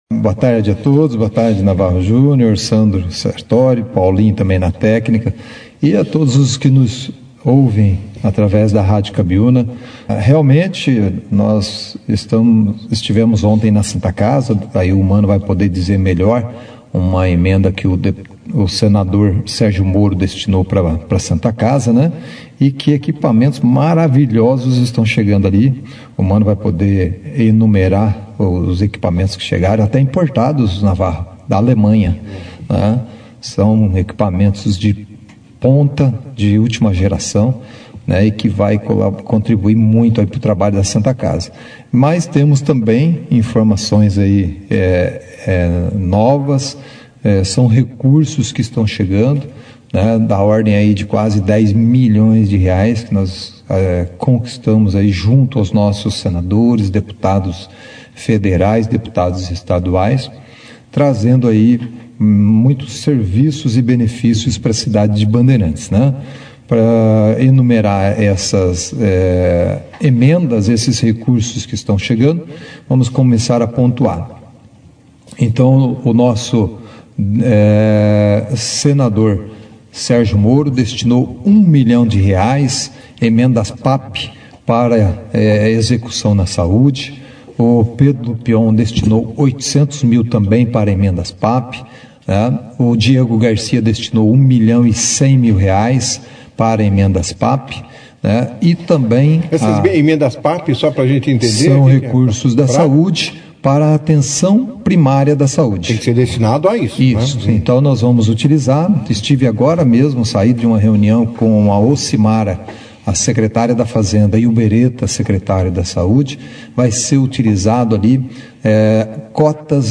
Prefeito Jaelson Ramalho Mata e Vice Prefeito Manoel Afonso Pirola Vieira participaram da edição desta quinta feira (05) do Jornal Operação Cidade, onde trouxeram muitas novidades boas para nosso município.